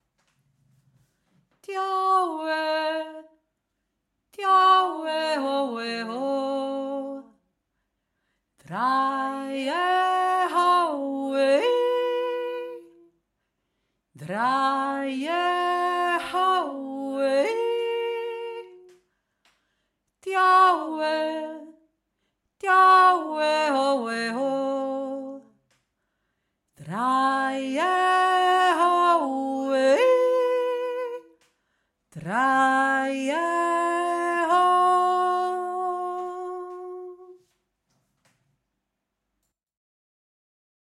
Der Kanon
erste Stimme
dio-e-erste-stimme.mp3